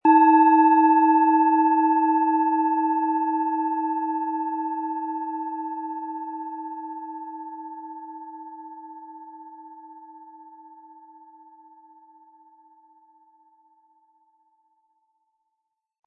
Die Planetenklangschale Wasserstoffgamma ist handgefertigt aus Bronze.
Im Sound-Player - Jetzt reinhören können Sie den Original-Ton genau dieser Schale anhören.
MaterialBronze